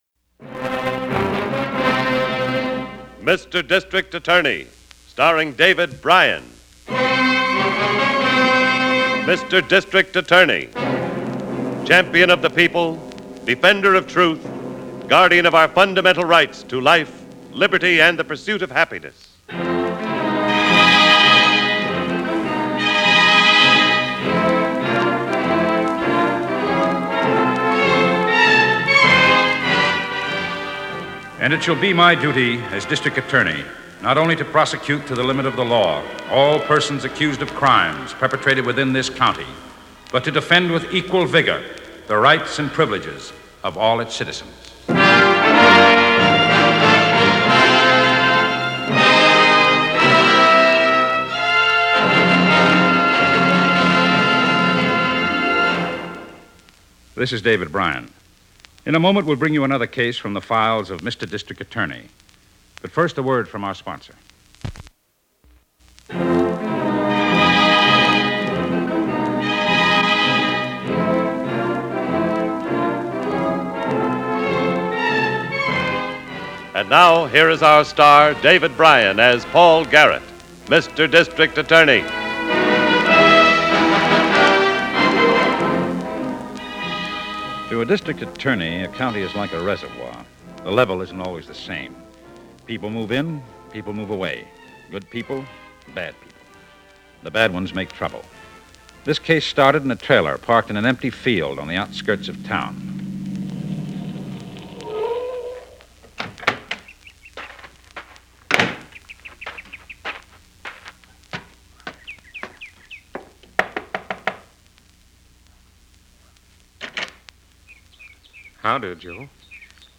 District Attorney is a radio crime drama, produced by Samuel Bischoff, which aired on NBC and ABC from April 3, 1939, to June 13, 1952 (and in transcribed syndication through 1953).